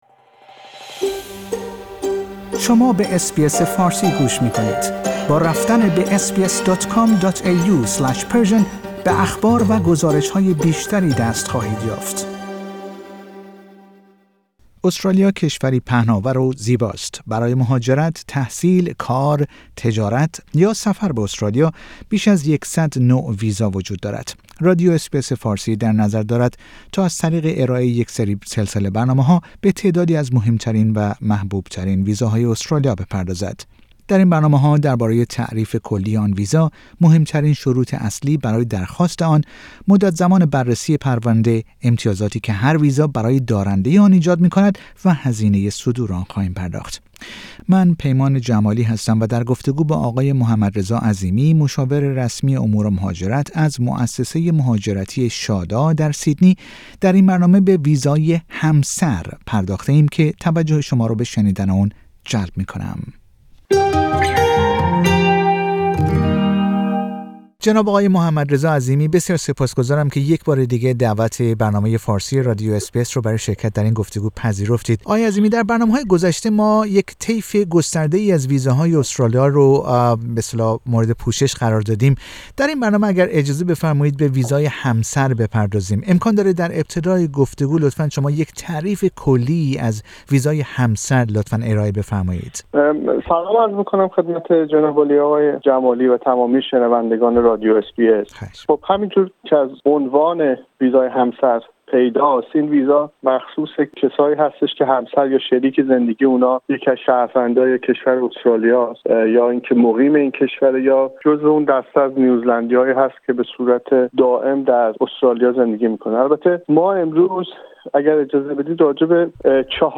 در گفتگو